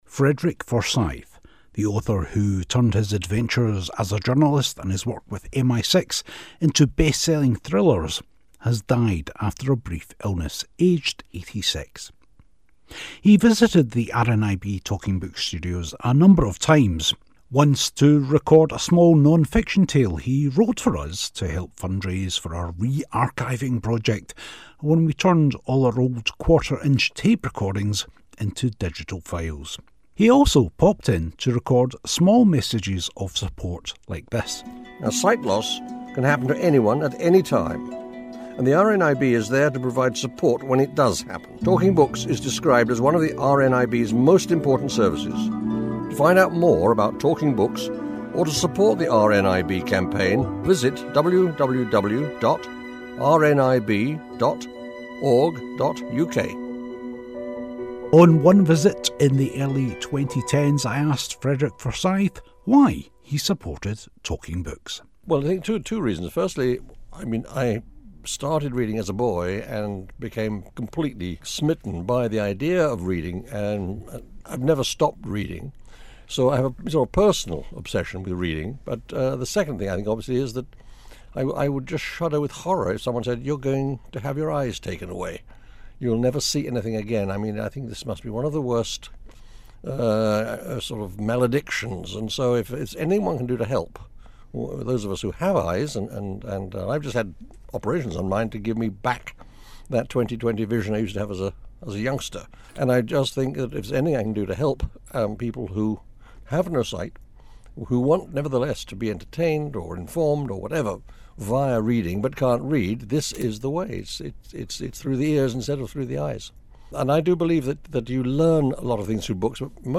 Frederick Forsyth at the RNIB Studios